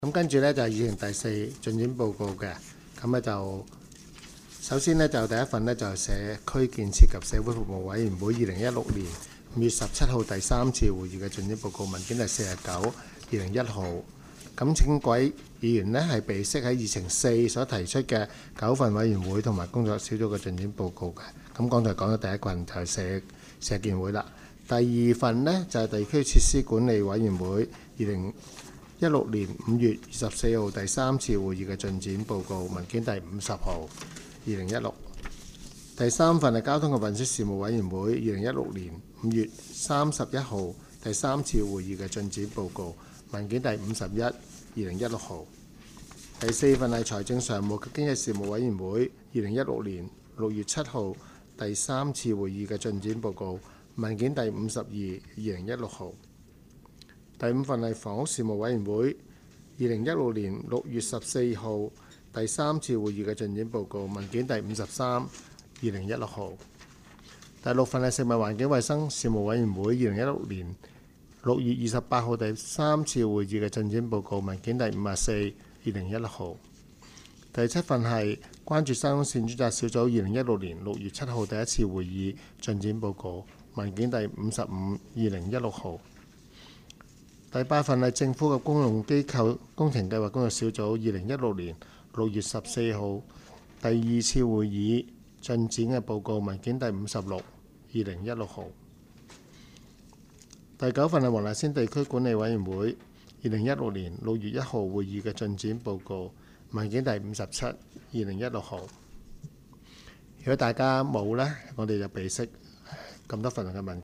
区议会大会的录音记录
黄大仙区议会第五次会议